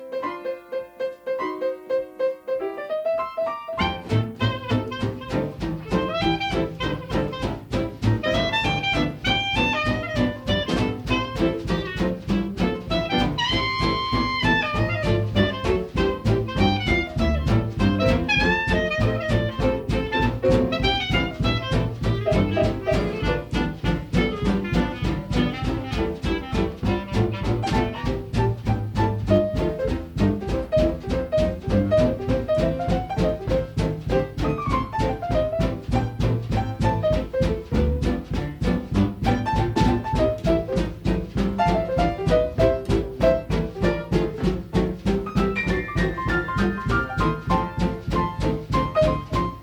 guitare
trompette
clarinette
piano
contrebasse
batterie.
vibraphone